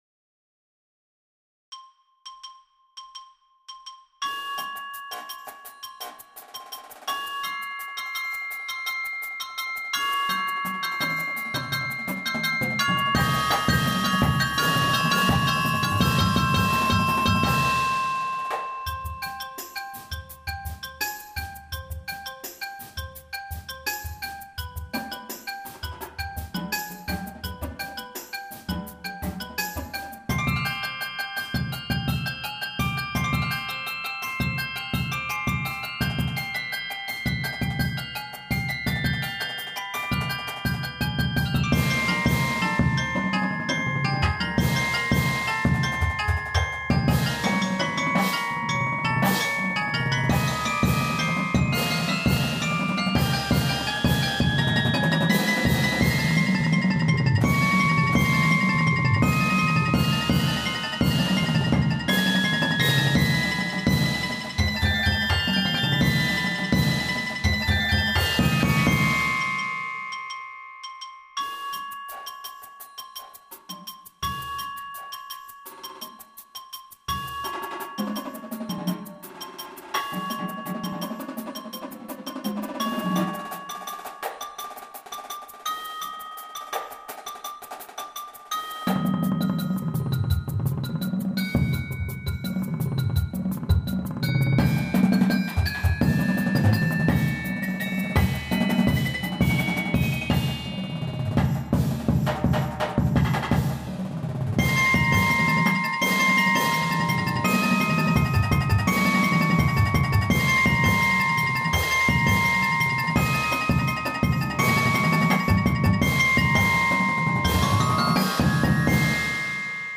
Snares
Tenors
5 Bass Drums
Marching Cymbals
Bells 1, 2
Xylo 1, 2
Auxiliary Percussion 1, 2, 3